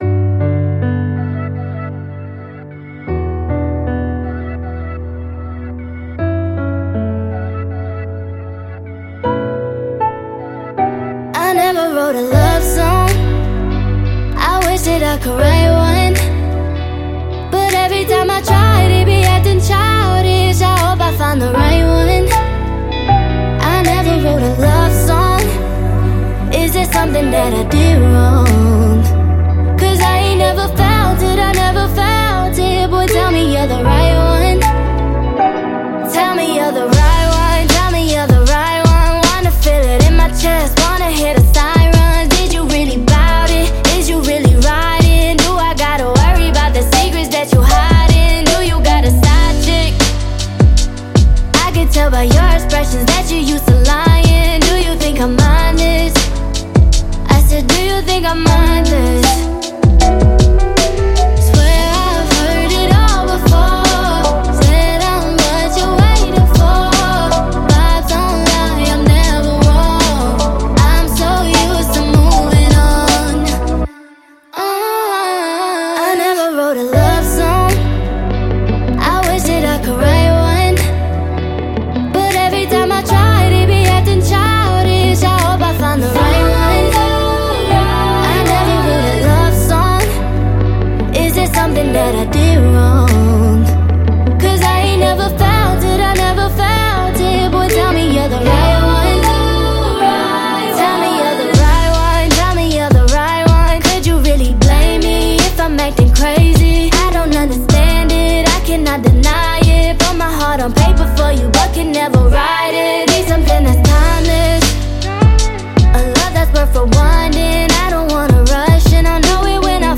# R&B